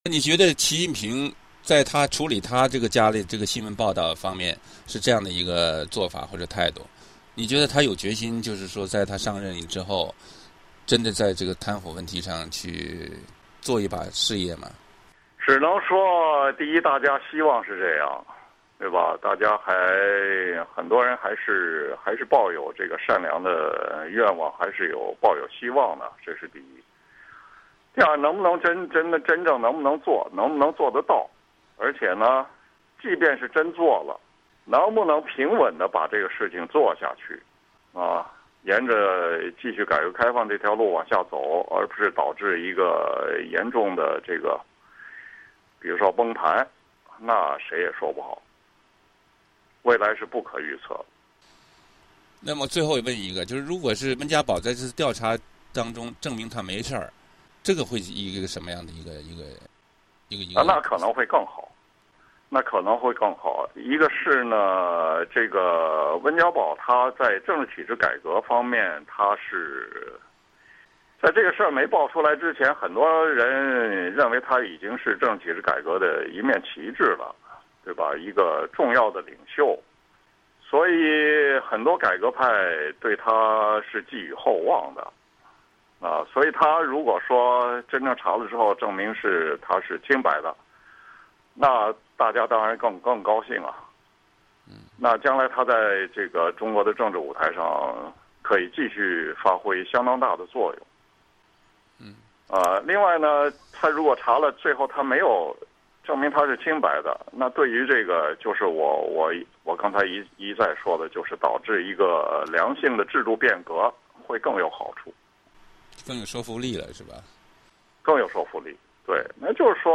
VOA专访